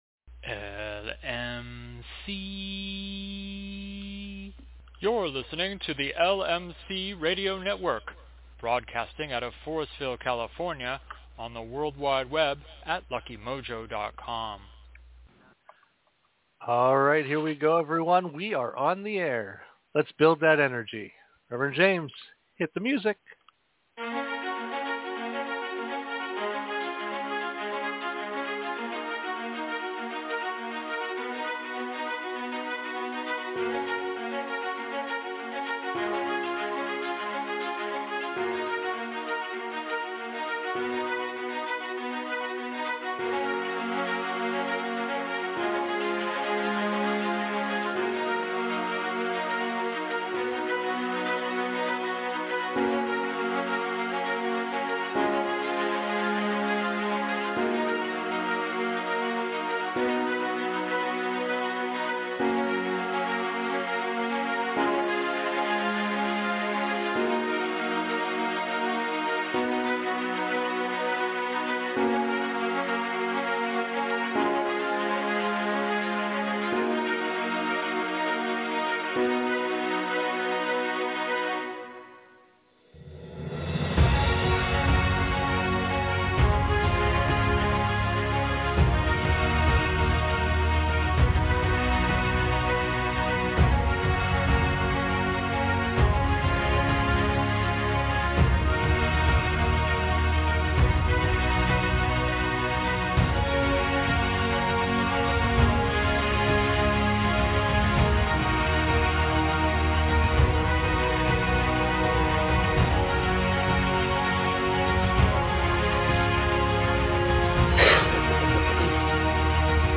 We begin this show with an update from our guest followed by a discussion on how to respectfully and ethically work with graveyard dirt and other considerations of graveyard work. In many spiritual traditions practitioners call upon the spirits of the dead for help.